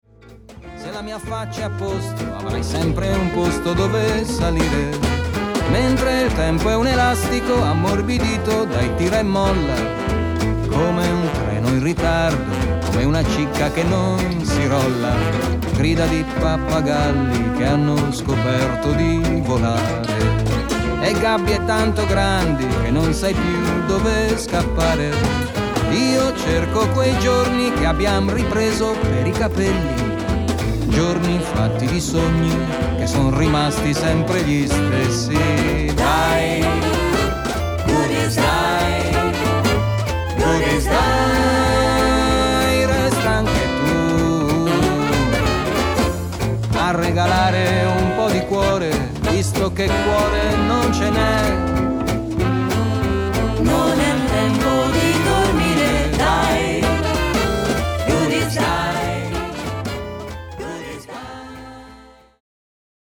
chitarra e voce